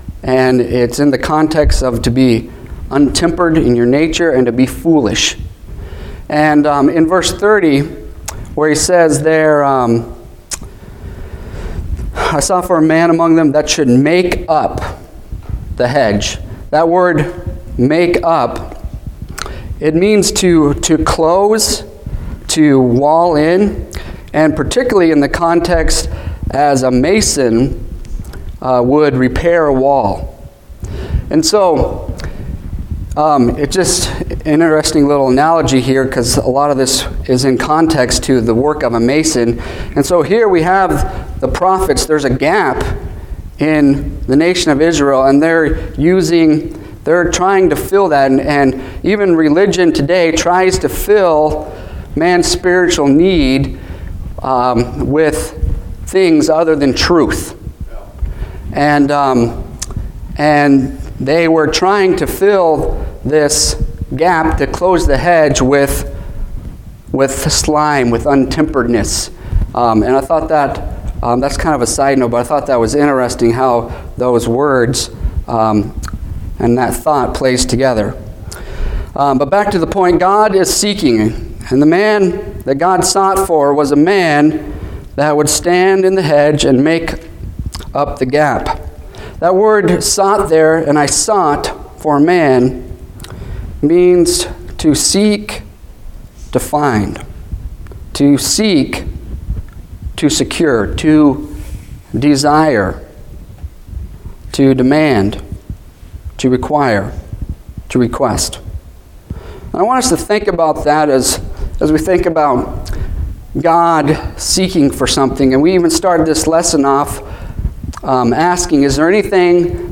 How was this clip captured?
Passage: Ezeikel 22:23 Service Type: Wednesday Evening